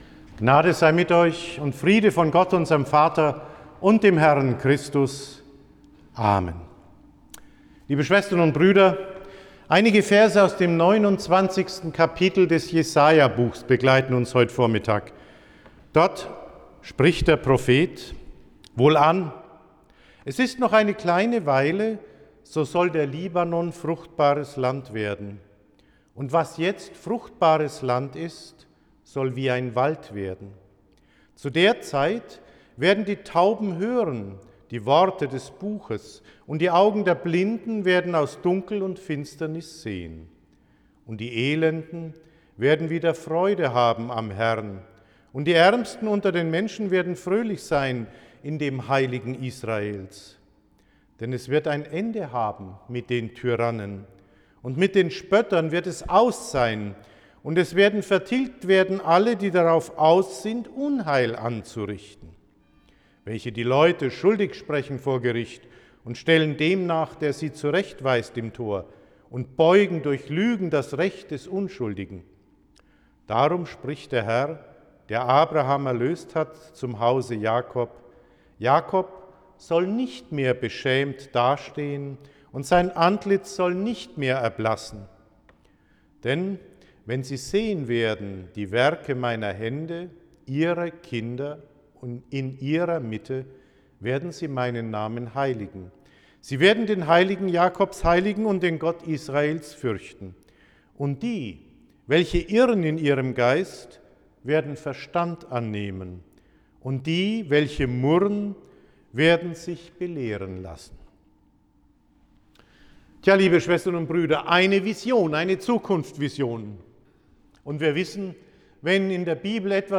1. Mai 2024, Rogate, 150 Jahre Freiwillige Feuerwehr Ditterswind, Lukasevangelium 11,1-13.